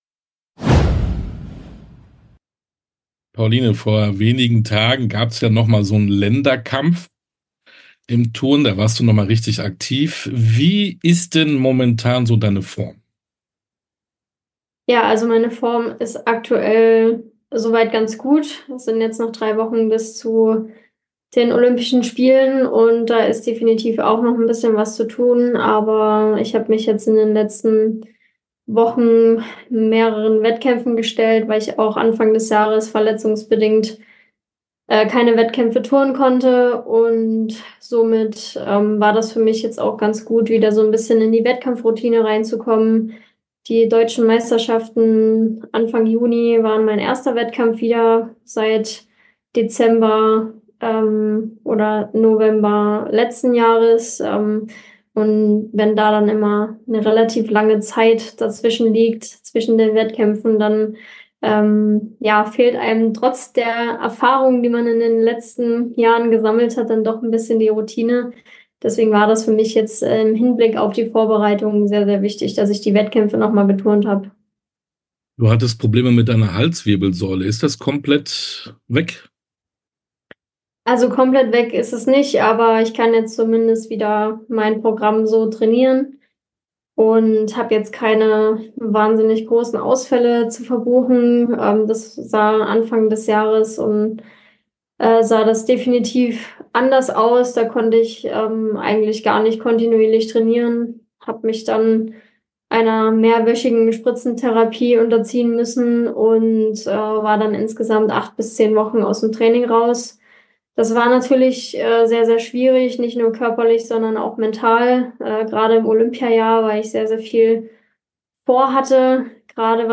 Sportstunde - Interview komplett Pauline Schäfer Betz, Turnerin ~ Sportstunde - Interviews in voller Länge Podcast